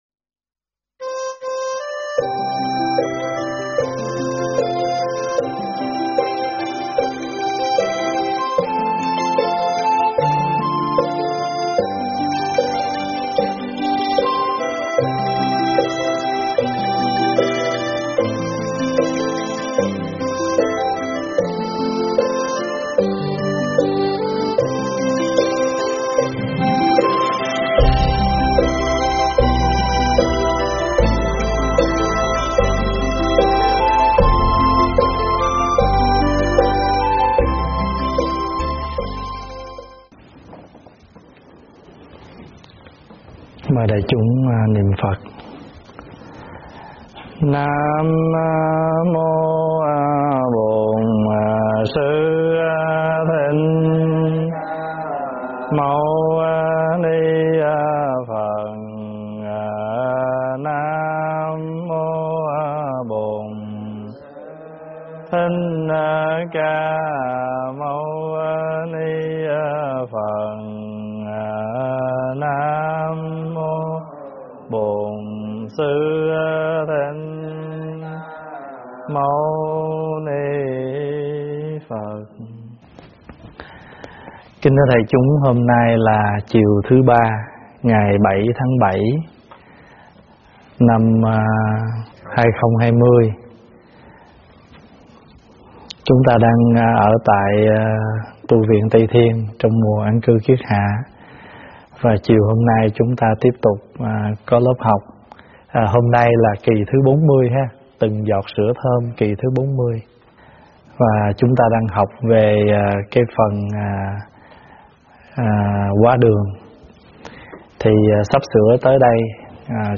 Nghe mp3 thuyết pháp Từng Giọt Sữa Thơm 40
giảng tại Tv Tây Thiên